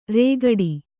♪ rēgaḍi